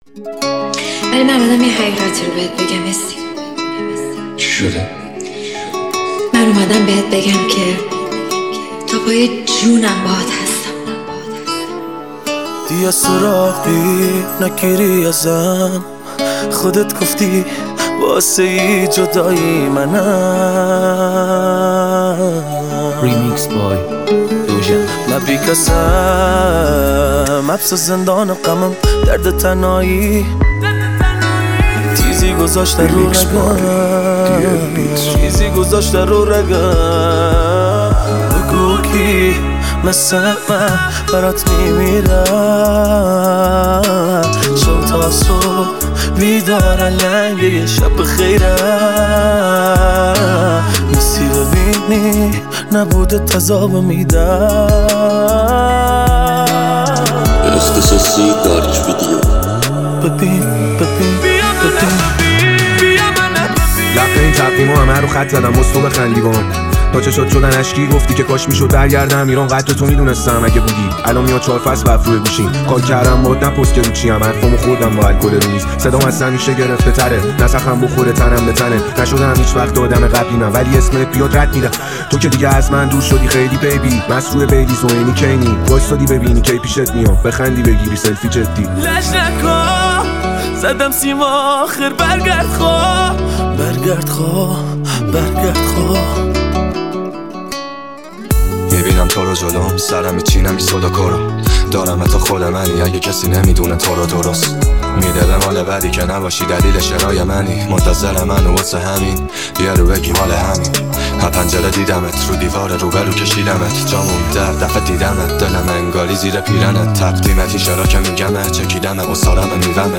• آهنگ جدید ~ ریمیکس